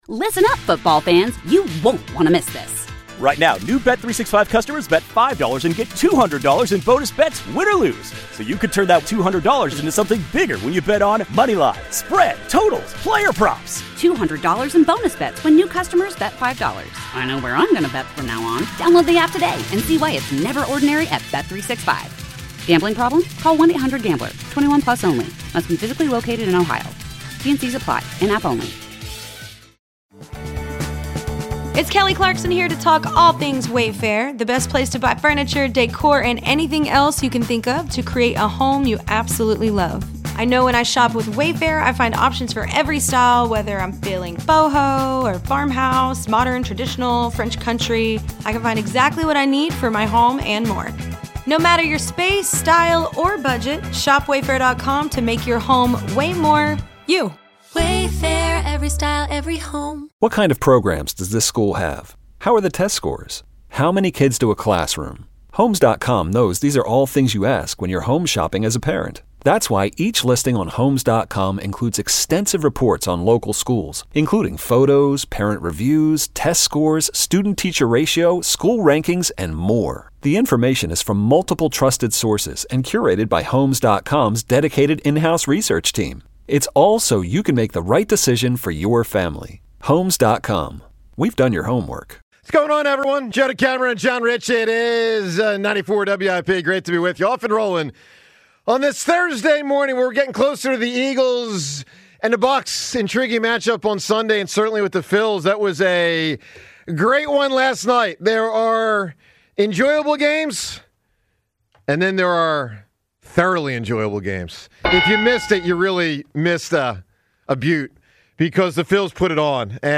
The 94WIP Morning Show delivers everything Philly sports fans crave — passionate takes, smart analysis, and the kind of raw, authentic energy that defines the city.